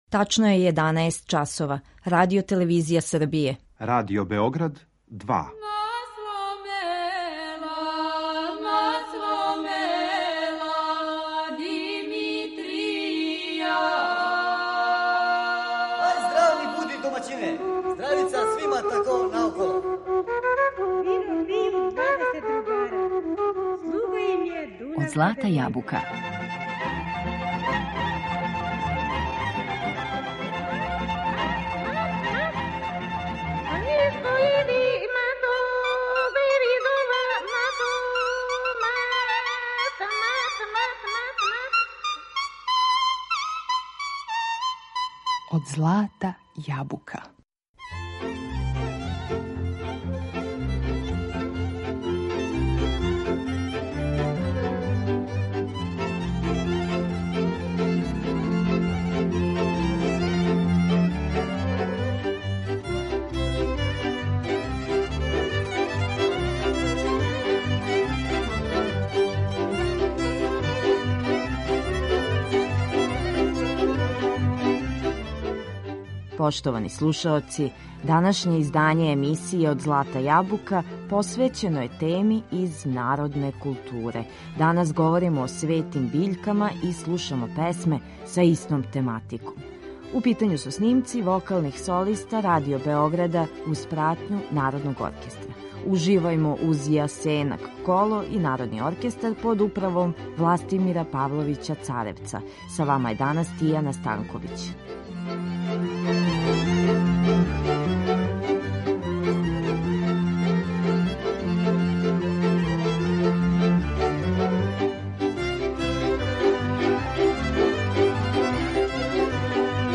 Говорићемо о светим биљкама и слушати песме посвећене истој тематици. У питању су снимци вокалних солиста Радио Београда уз пратњу Народног оркестра.